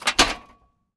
metal_hit_small_10.wav